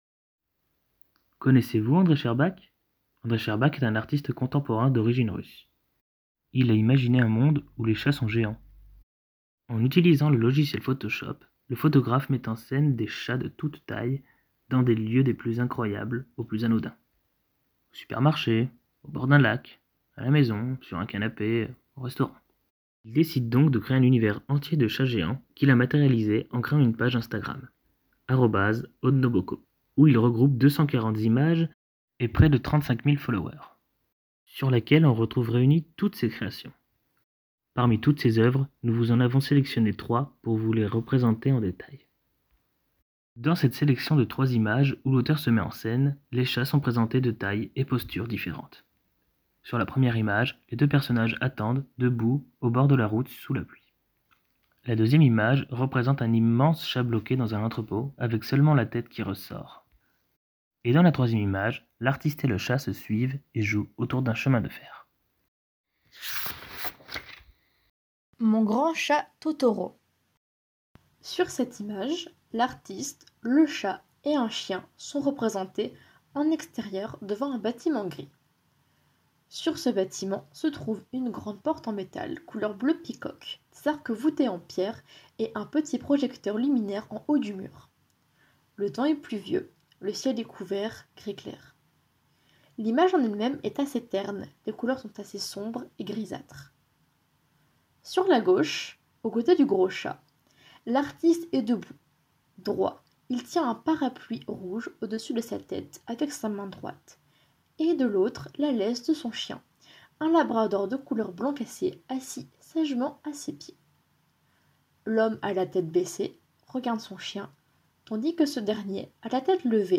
Les participant·e·s à l'atelier ont mené cette activité en groupes, chacun d'eux proposant une visite virtuelle parmi les œuvres mises en ligne par l'artiste.